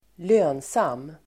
Uttal: [²l'ö:nsam:]